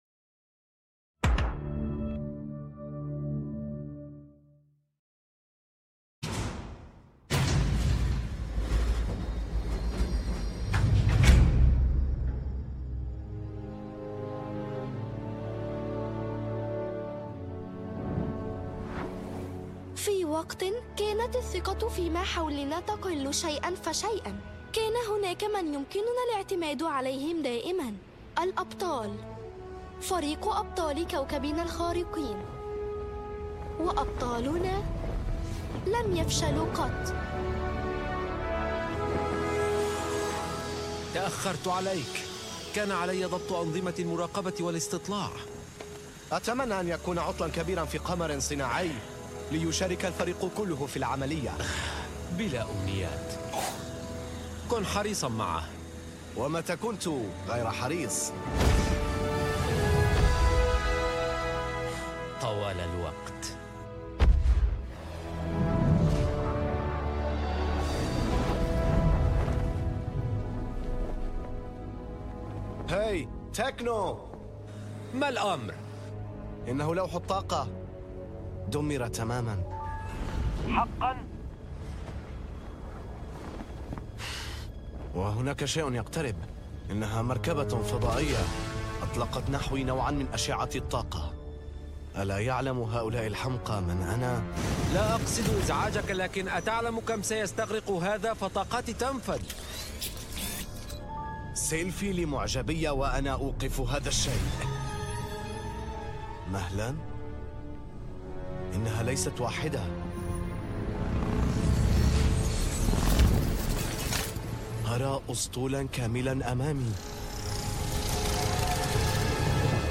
إعلان فيلم يمكننا أن نصبح ابطال 2024 - We Can Be Heroes starring Priyanka Chopra Jonas فيلم مدبلج